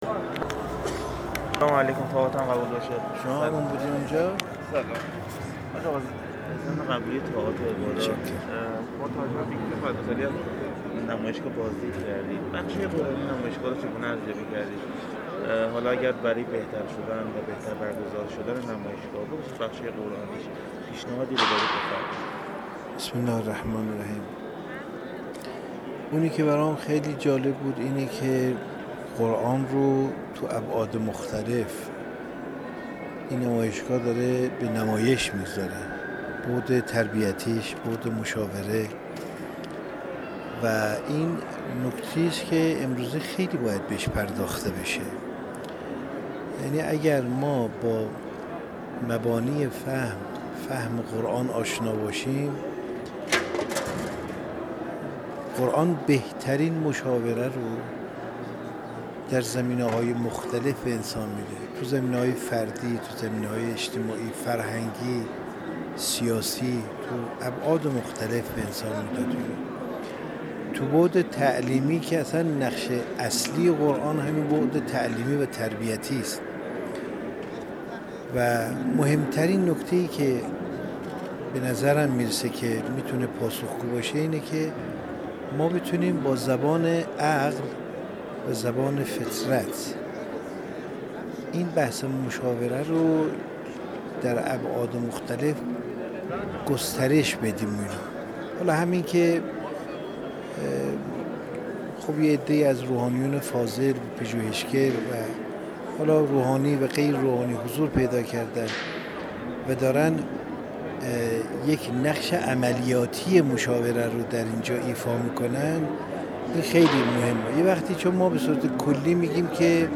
عضو مجلس خبرگان رهبری در گفت‌وگو با ایکنا:
آیت‌الله رضا رمضانی، عضو مجلس خبرگان رهبری و دبیرکل مجمع جهانی اهل بیت(ع)، در حاشیه بازدید از بیست‌ونهمین نمایشگاه بین‌المللی قرآن کریم در گفت‌وگو با خبرنگار ایکنا، درباره کیفیت نمایشگاه اظهار کرد: آنچه که نظر من را در این نمایشگاه به خود جلب کرد این است که قرآن در ابعاد مختلف مانند بُعد تربیتی و بُعد آموزشی به معرض نمایش درآمده و این نکته‌ای است که باید مدنظر و به آن پرداخته شود.